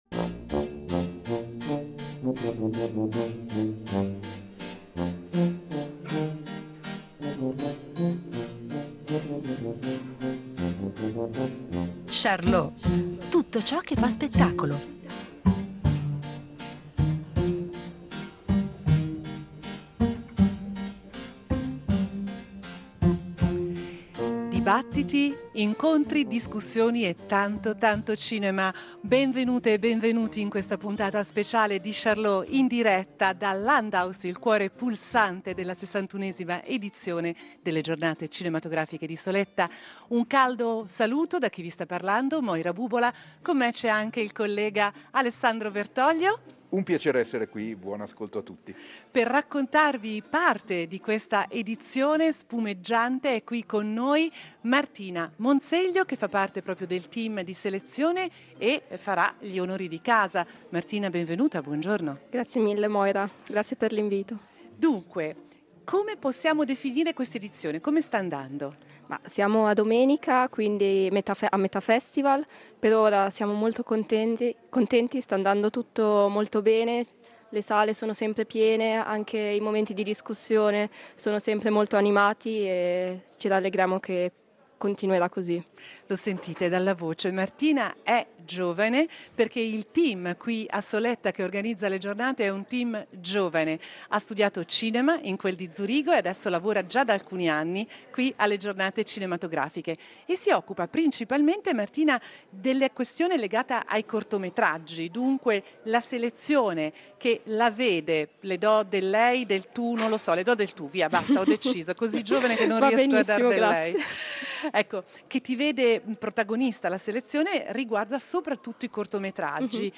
Durante le Giornate cinematografiche di Soletta , “Charlot”, in diretta dalla capitale del Cantone, cercherà di capire a che punto siamo e come si sono attrezzati color che lavorano nel settore.